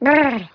voice_brbrbr.wav